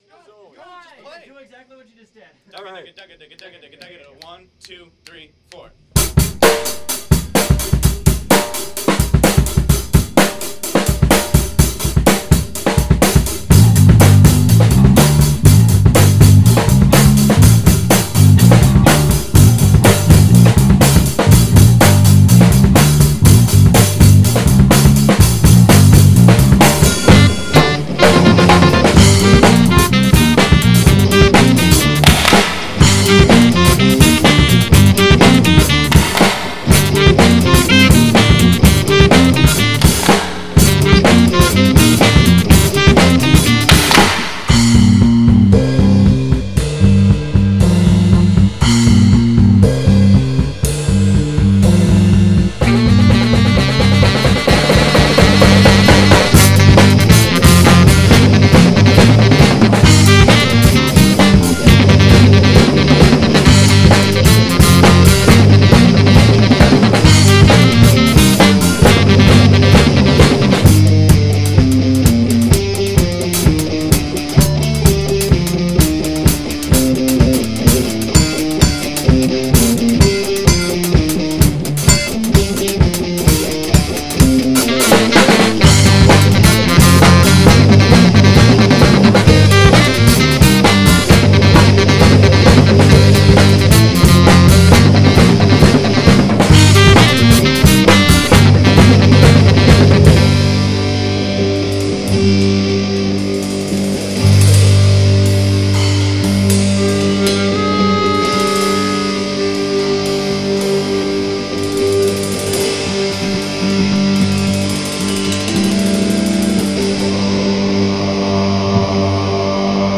dancehall.mp3